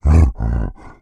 MONSTER_Effort_09_mono.wav